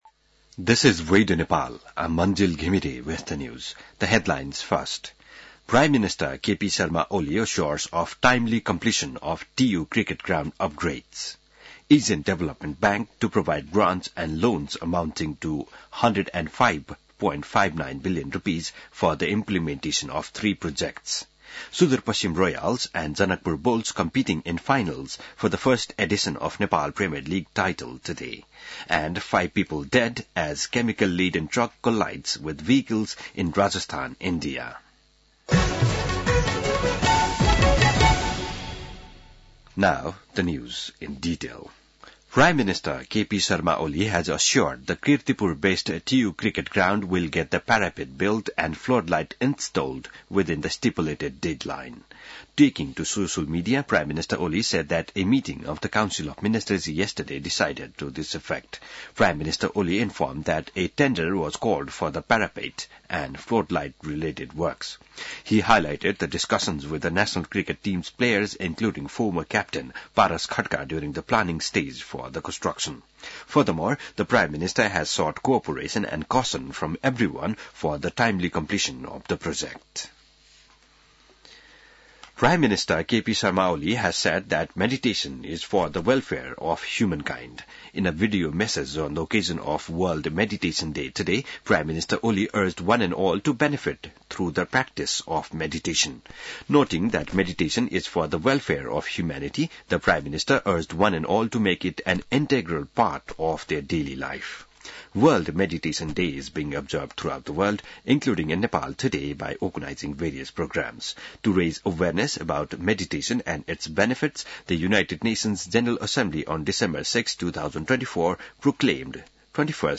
बिहान ८ बजेको अङ्ग्रेजी समाचार : ७ पुष , २०८१